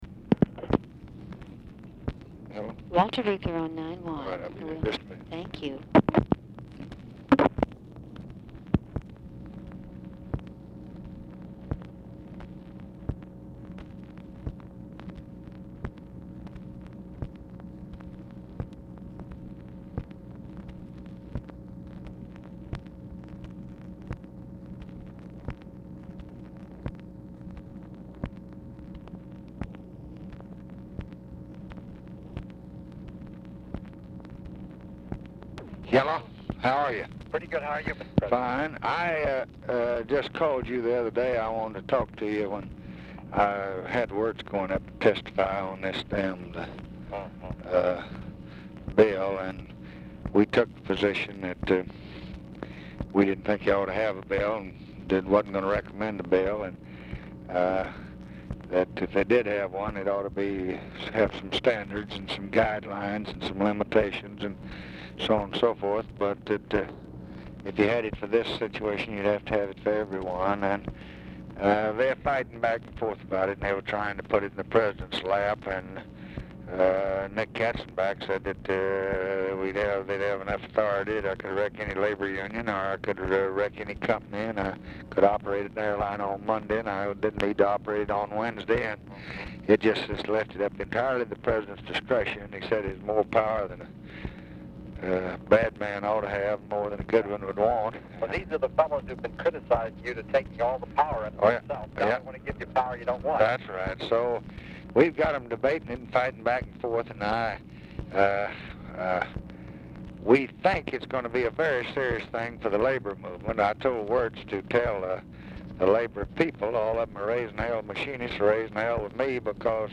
Telephone conversation # 10549, sound recording, LBJ and WALTER REUTHER, 8/3/1966, 7:36PM
Format Dictation belt
Location Of Speaker 1 Oval Office or unknown location